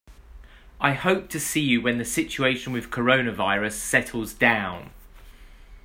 音声（イギリス）つきなので、まねして発音してみてください。